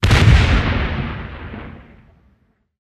bomb.ogg